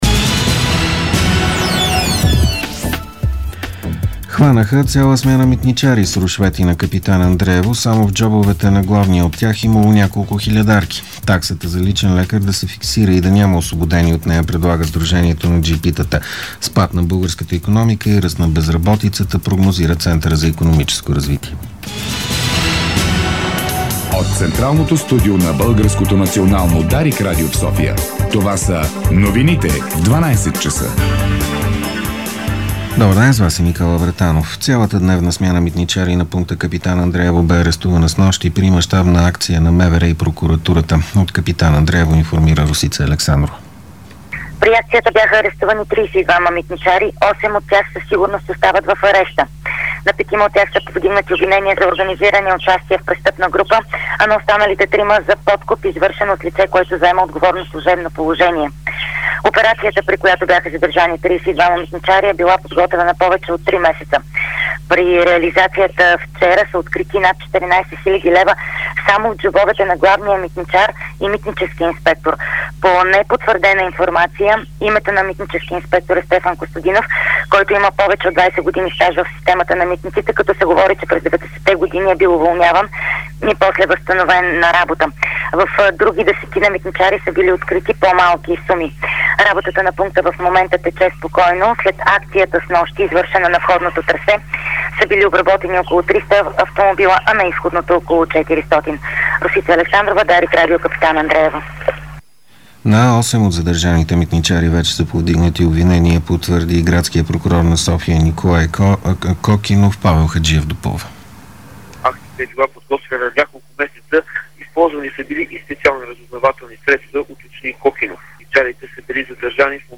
Обедна информационна емисия - 03.05.2012